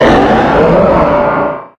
infinitefusion-e18/Audio/SE/Cries/CAMERUPT_1.ogg at develop-6.6
CAMERUPT_1.ogg